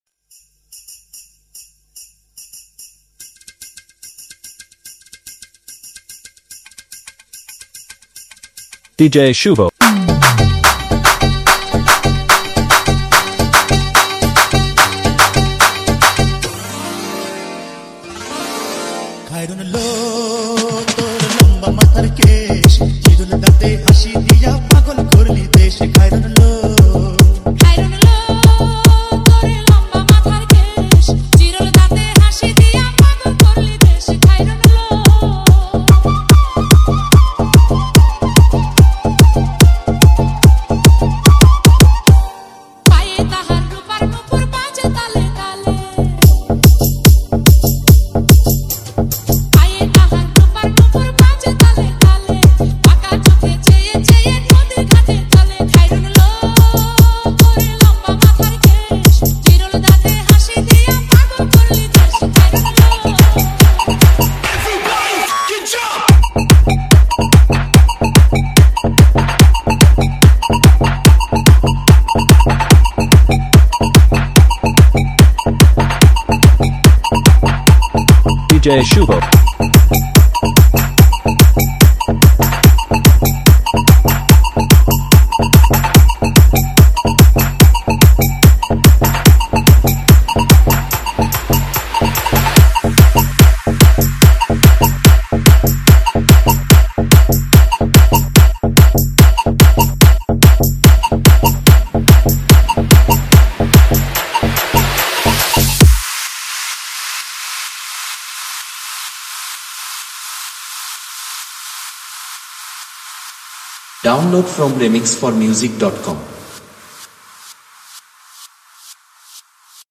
Category : Bangla Remix Song